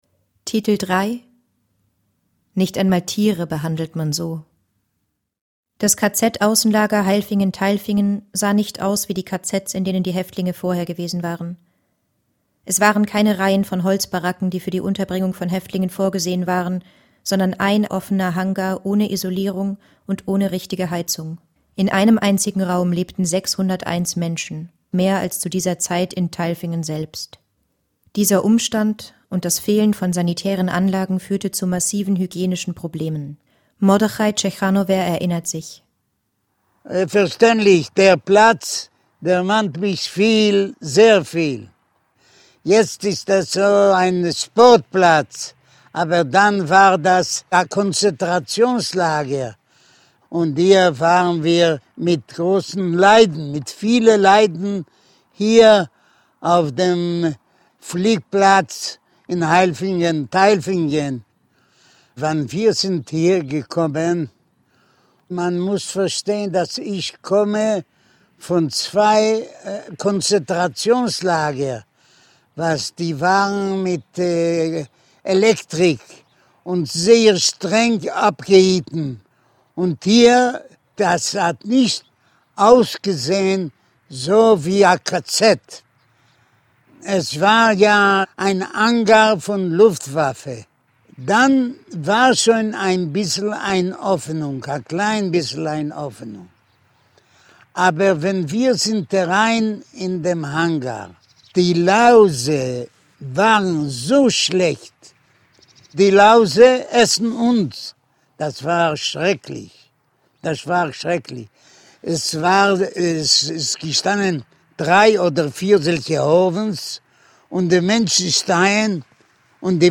Audioguide Titel 3
mittlere Audioqualität